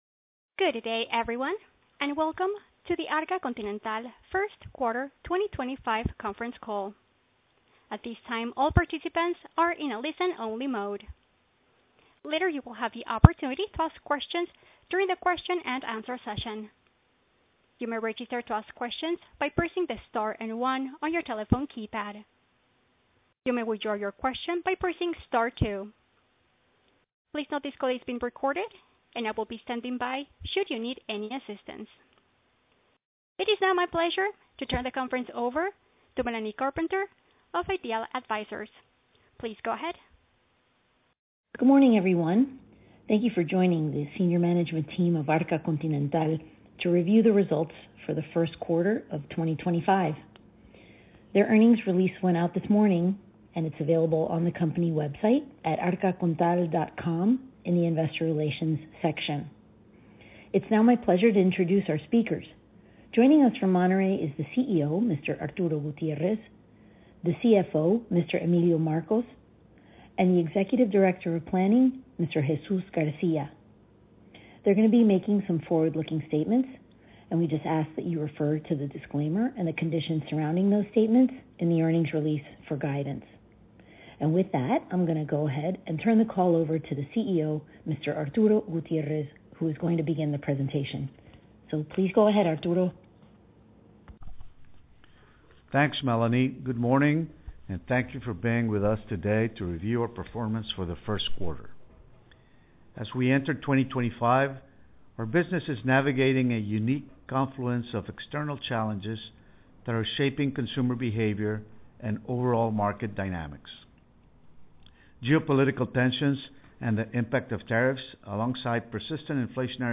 Conferencias Telefónicas, Inversionistas | Arca Continental